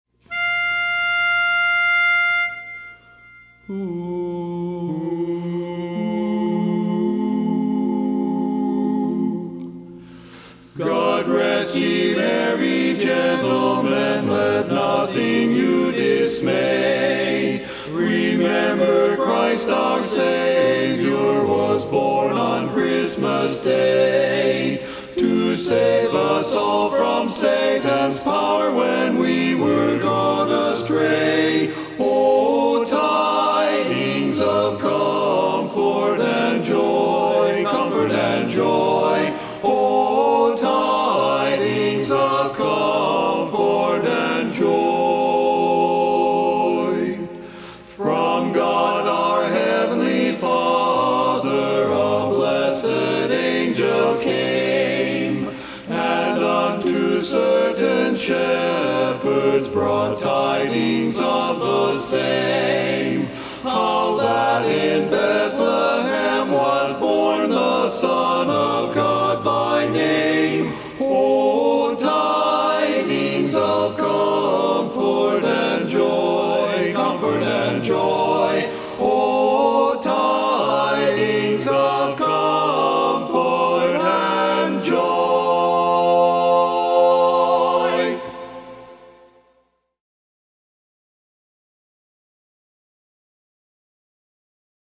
Christmas Songs
Barbershop
Bari